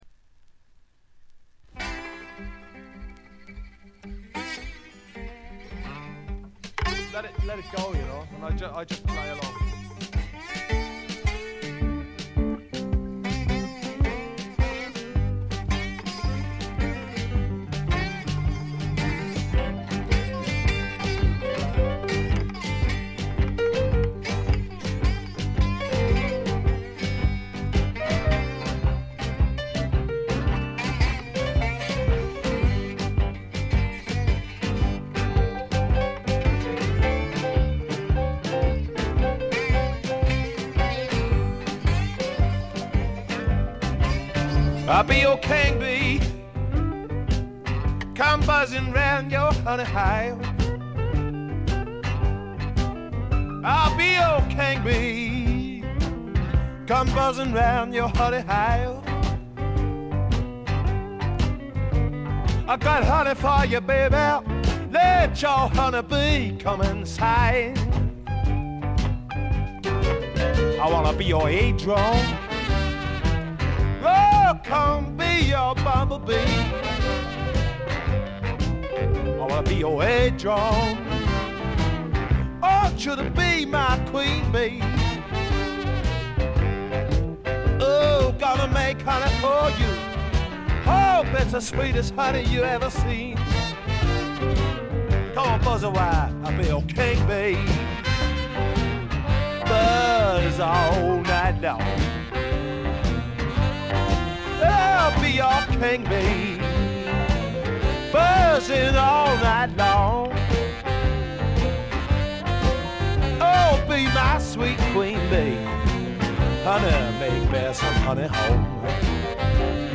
バックグラウンドノイズに軽微なチリプチ。
メランコリックで屈折した英国的翳りが底を流れているところが本作の最大の魅力かな？
試聴曲は現品からの取り込み音源です。